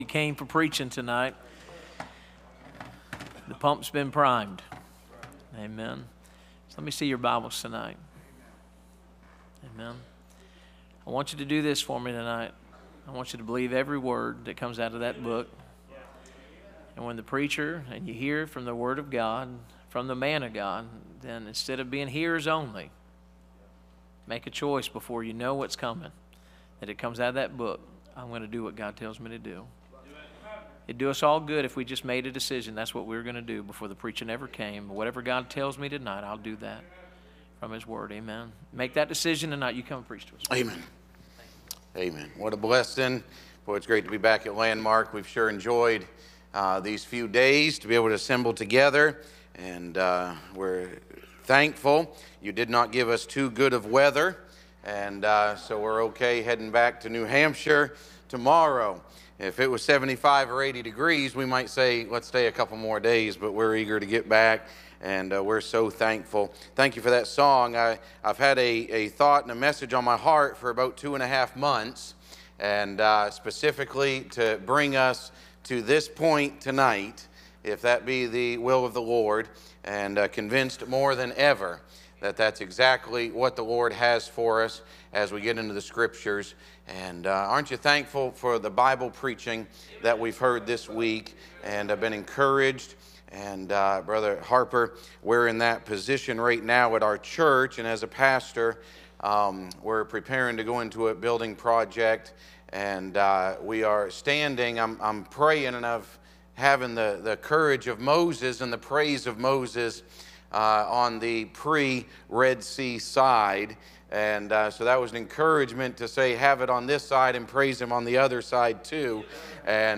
Series: 2025 Bible Conference